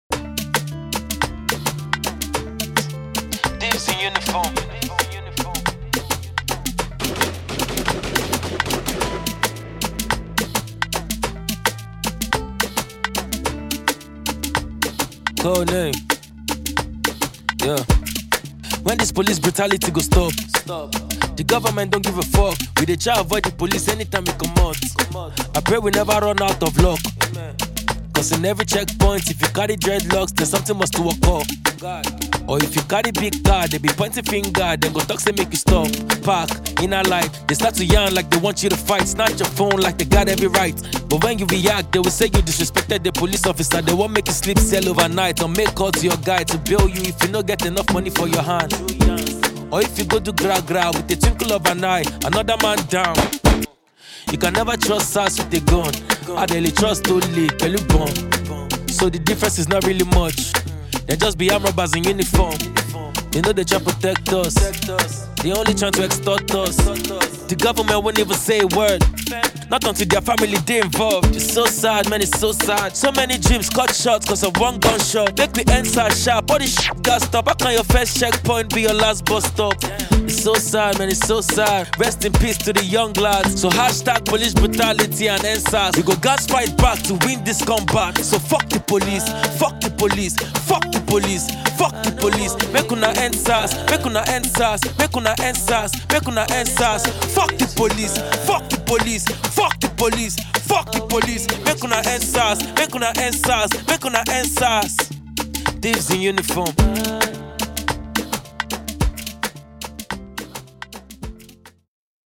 rap artist